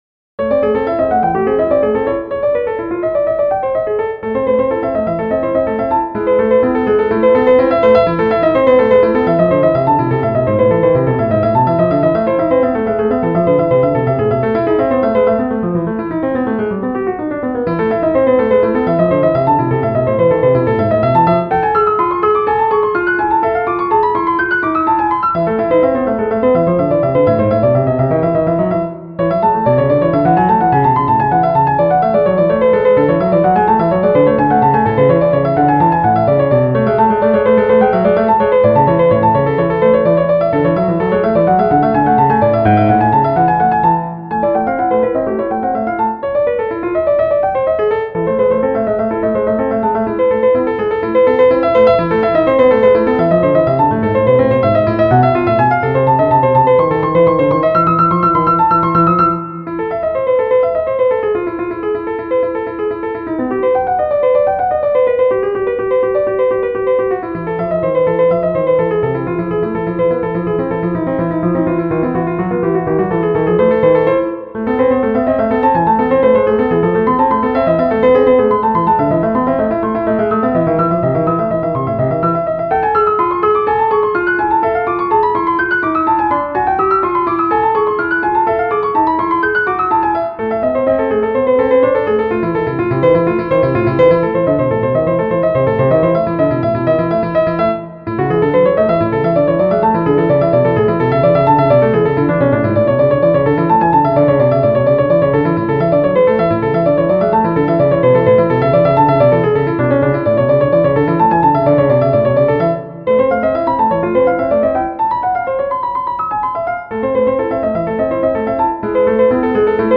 ☆Like bach's inventions(15EDO)